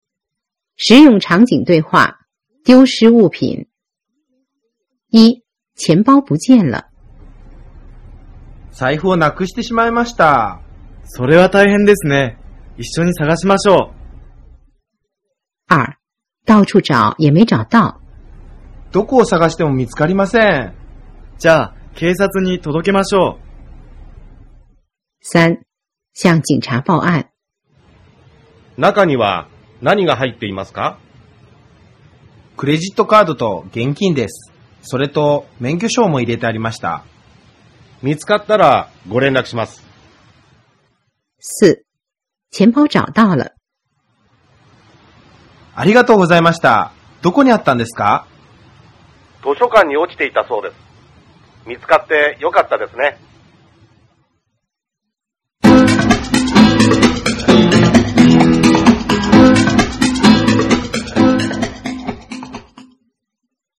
實用場景對話 9 — 丟失物品
Unit09Dialog.mp3